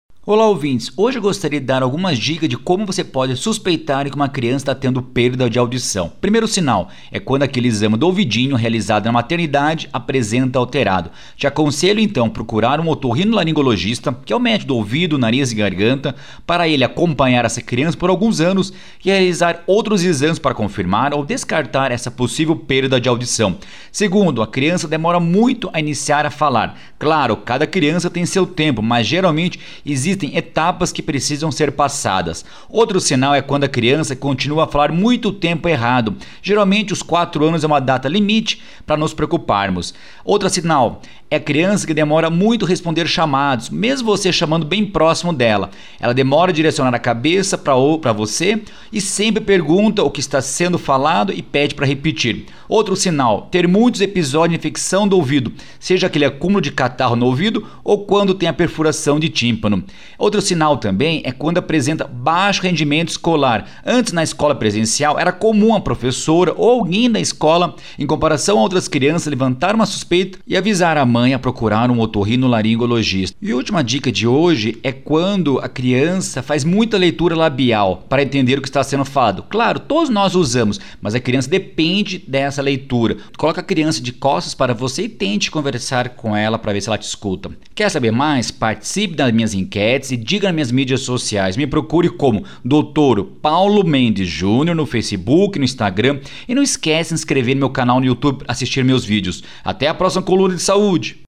Criança com perda de audição? Médico especialista explica sinais de suspeita